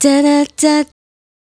Duh Duh Duh 152-D.wav